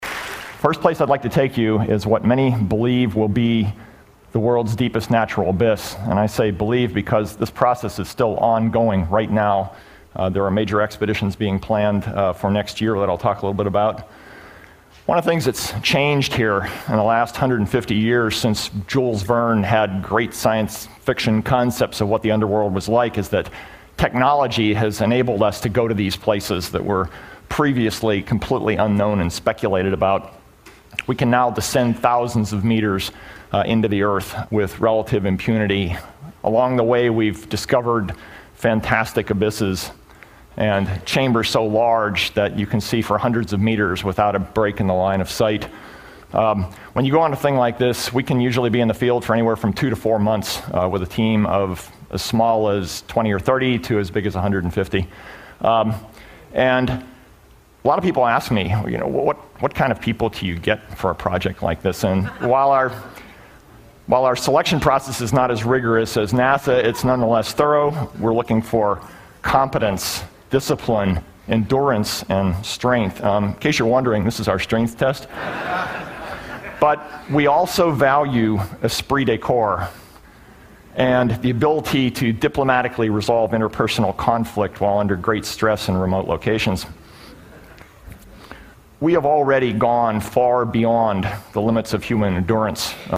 TED演讲:探索世界最深洞穴(1) 听力文件下载—在线英语听力室